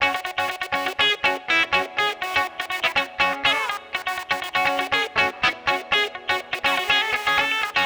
AGuitar.wav